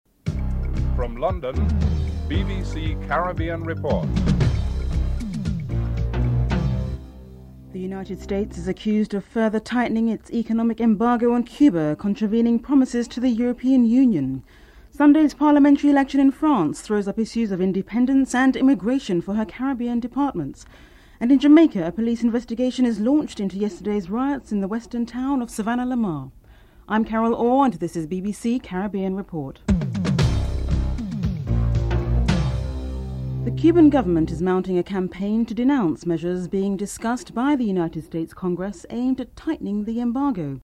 1. Headlines (00:00-00:32)